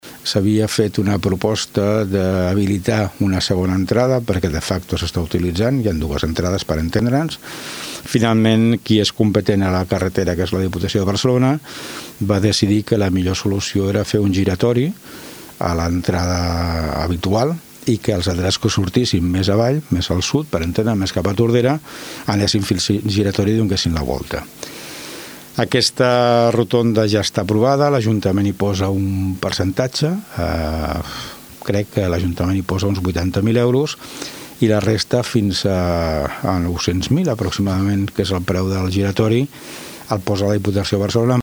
Escoltem a l’Alcalde de Tordera, Joan Carles Garcia.